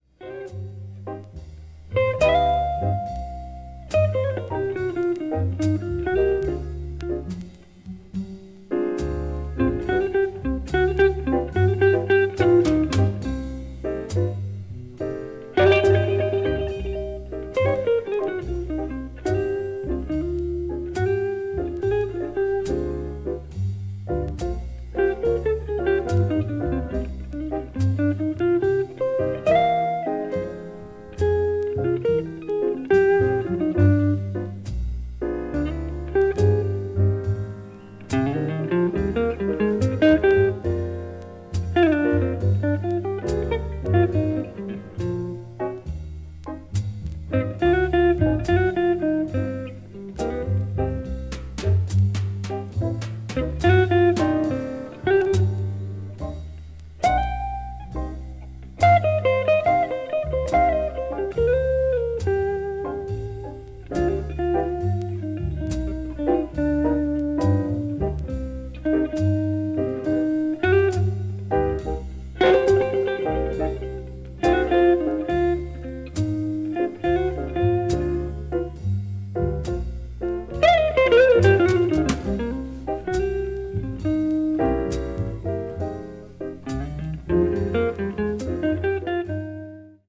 テンションを抑えながら哀愁に満ちたブルースを披露したA1
終始クールな雰囲気を携えた名演
blues jazz   hard bop   modern jazz